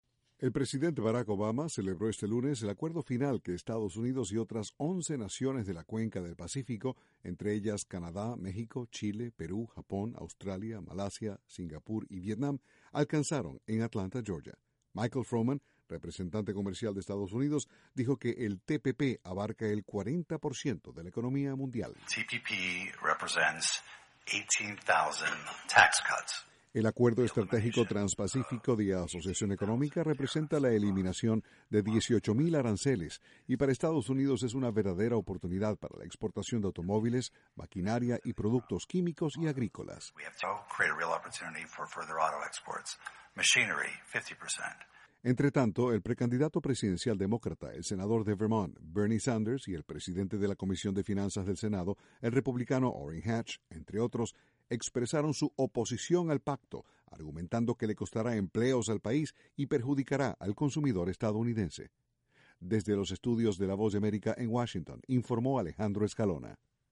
Estados Unidos anunció acuerdo comercial final con 11 naciones de la cuenca del Pacífico. Desde la Voz de América, Washington